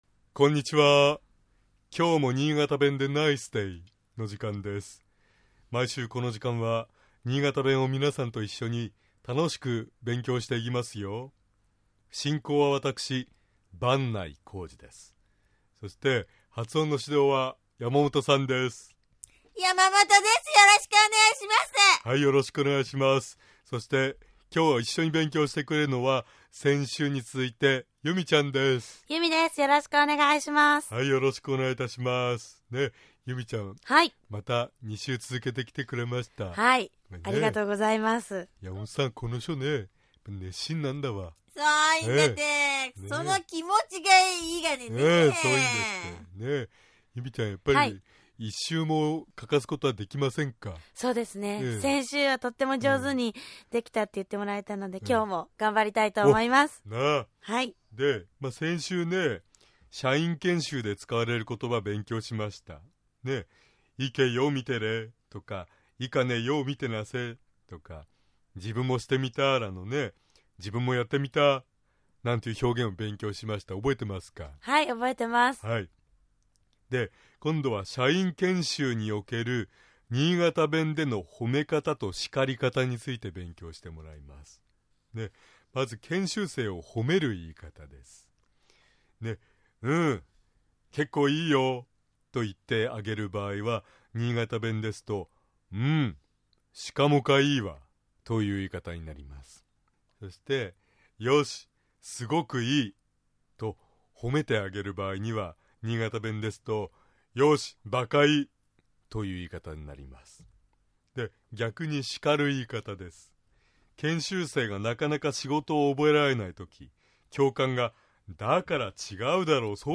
尚、このコーナーで紹介している言葉は、 主に新潟市とその周辺で使われている方言ですが、 それでも、世代や地域によって、 使い方、解釈、発音、アクセントなどに 微妙な違いがある事を御了承下さい。